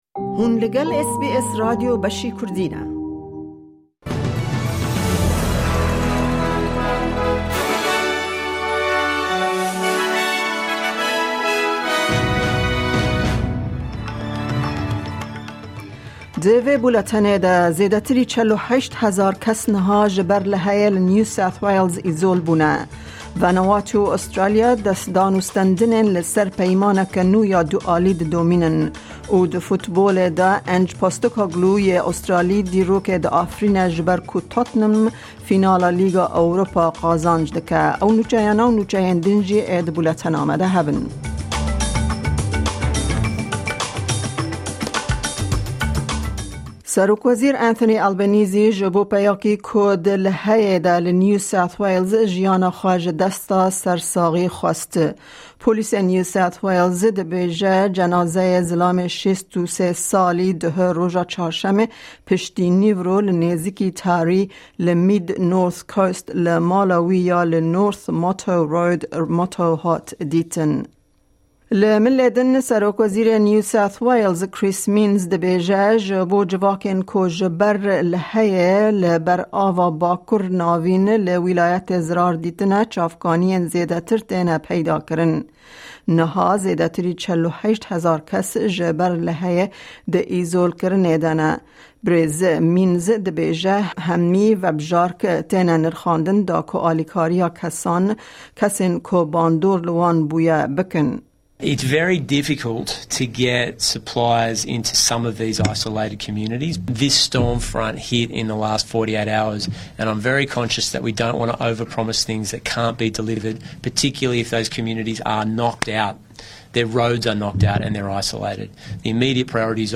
Kurdish News